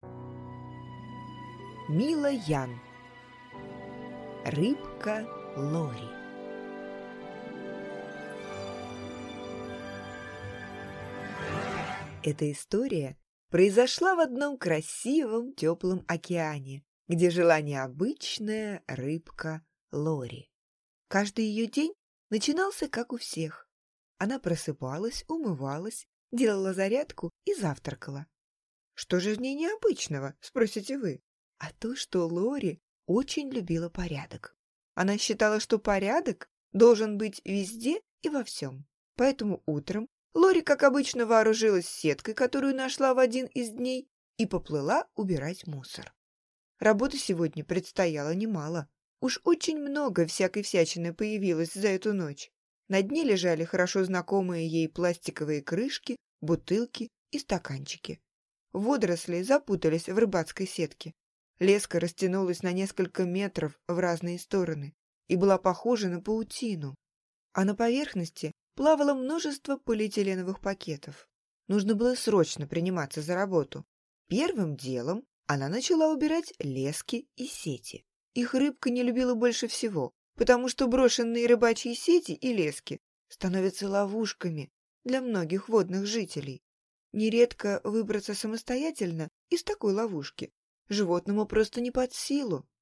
Аудиокнига Рыбка Лори | Библиотека аудиокниг